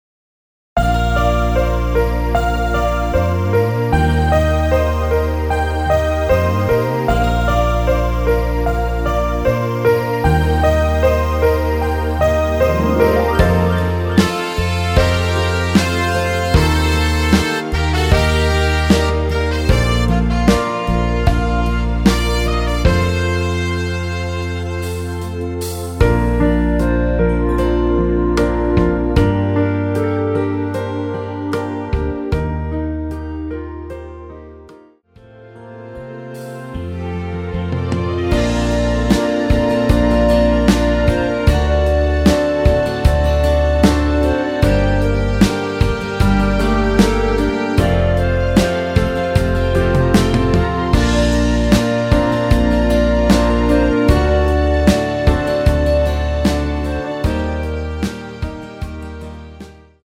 원키 멜로디 포함된 MR 입니다.(미리듣기 참조)
Bb
앞부분30초, 뒷부분30초씩 편집해서 올려 드리고 있습니다.
중간에 음이 끈어지고 다시 나오는 이유는